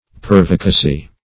Pervicacy \Per"vi*ca*cy\
pervicacy.mp3